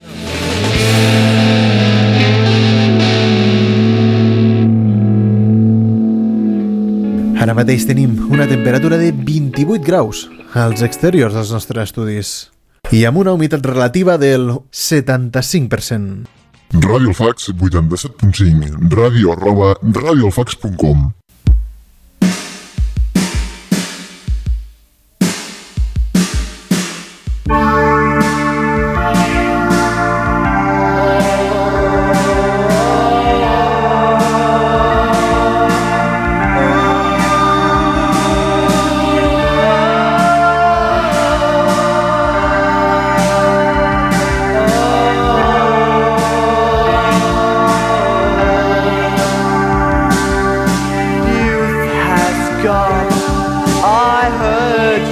Dades del temps, indicatiu de la ràdio i tema musical